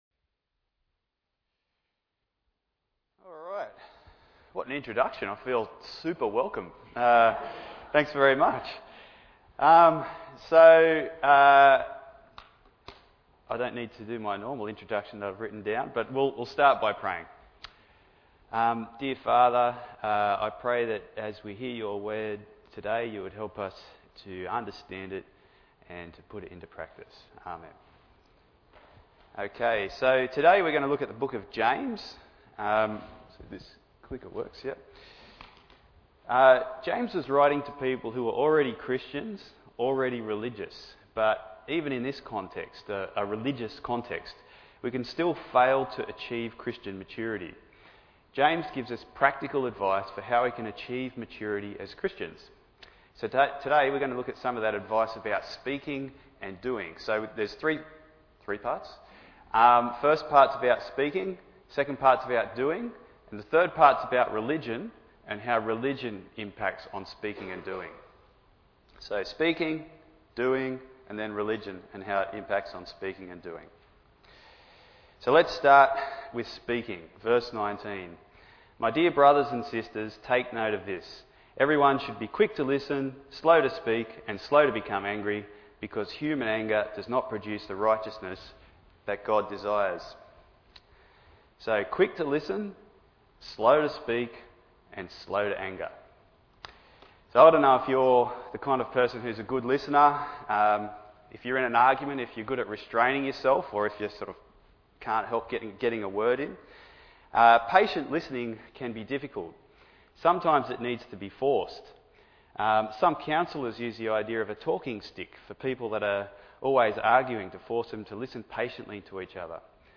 Bible Text: James 1: 19-27 | Preacher